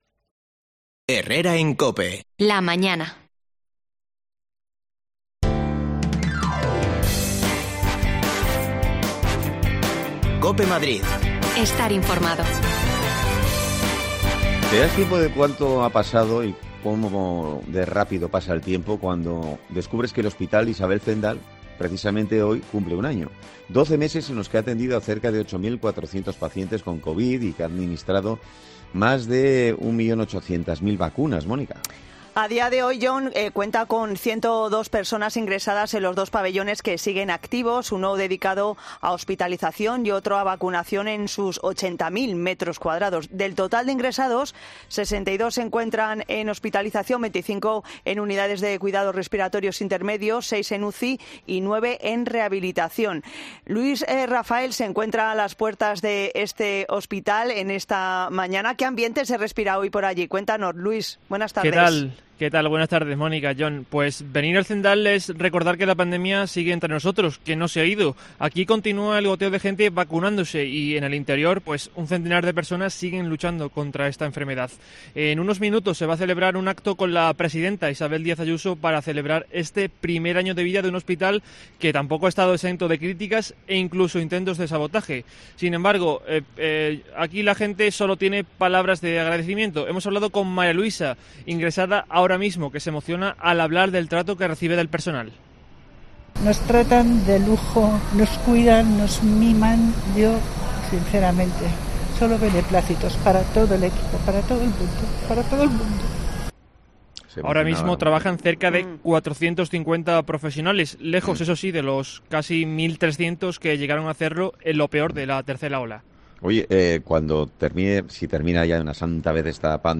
AUDIO: El Hospital Isabel Zendal cumple hoy un año. Nos pasamos por sus instalaciones para recordar todo lo que ha pasado durante estos doce meses
Las desconexiones locales de Madrid son espacios de 10 minutos de duración que se emiten en COPE , de lunes a viernes.